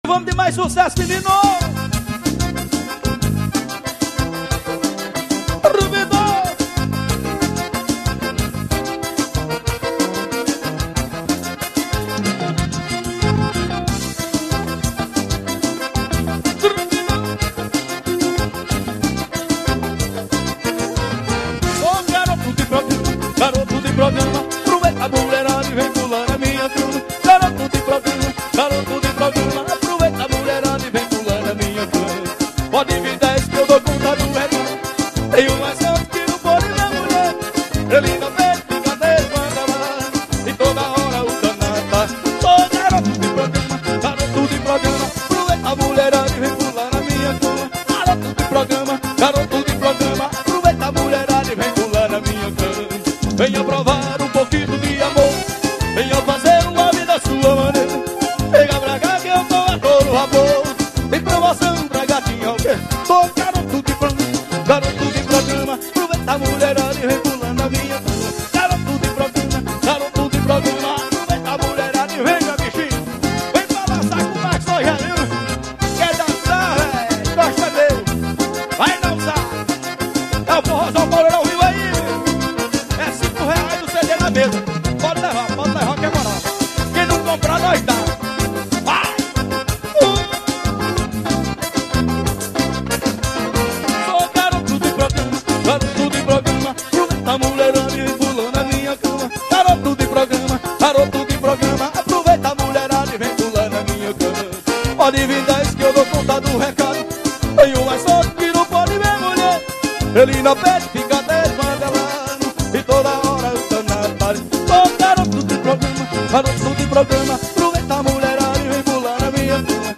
GRAVADO AO VIVO